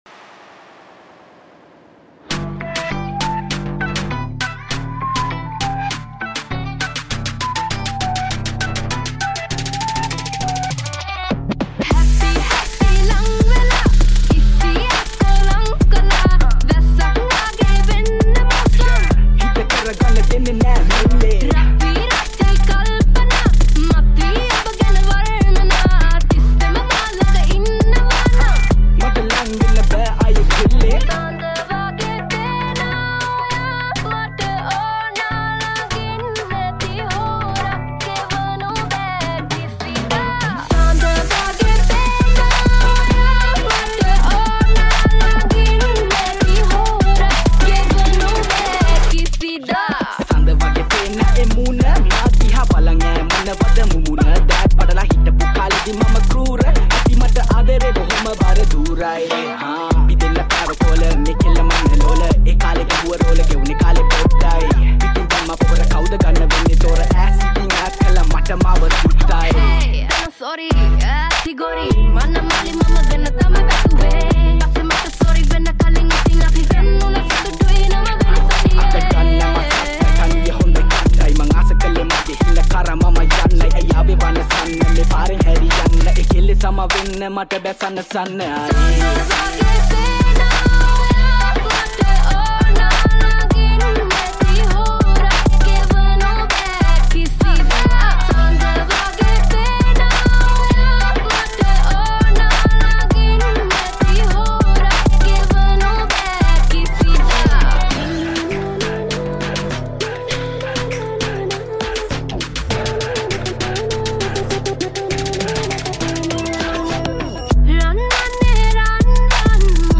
Trap Remix